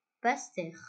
Basse-Terre (/bæsˈtɛər/, bas-TAIR; French: [bɑstɛʁ]